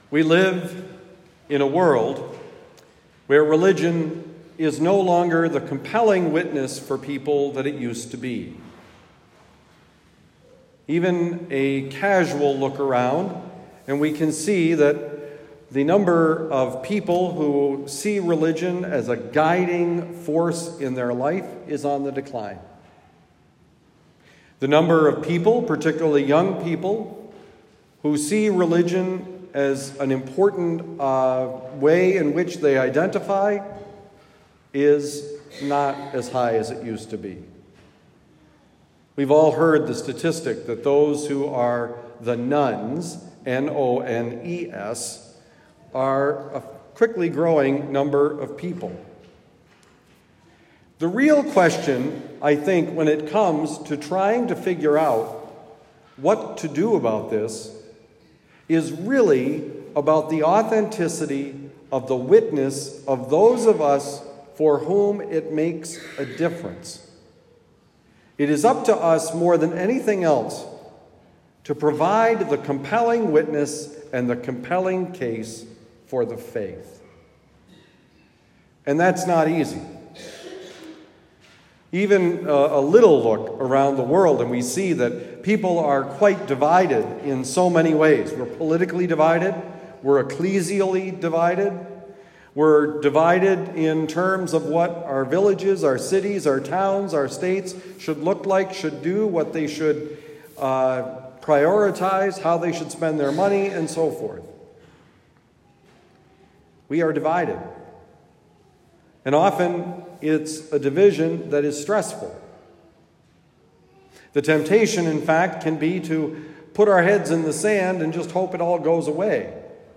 How to be noticed (in the right sense): Homily for Sunday, February 5, 2023
Given at Our Lady of Lourdes, University City, Missouri.